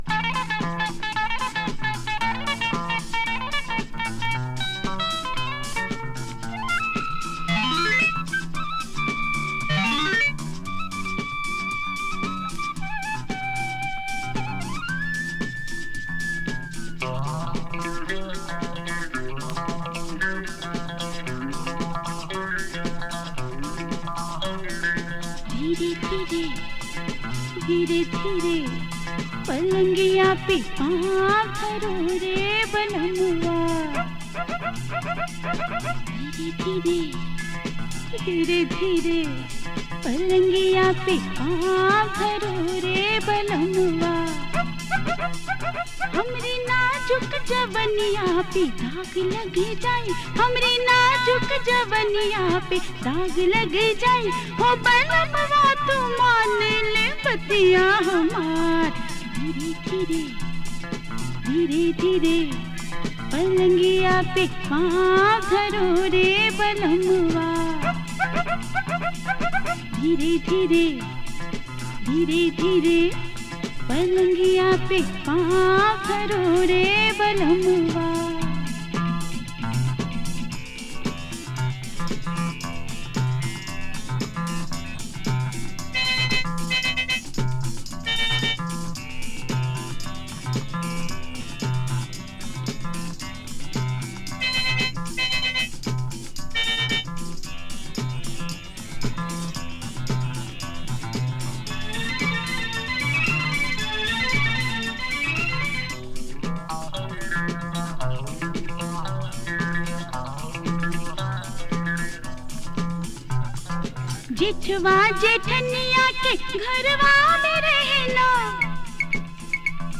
Groovy Bollywood Music!
サイケデリックな電子ディスコ・ファンク
【INDIA】【PSYCHEDELIC】【DISCO】【SYNTH POP】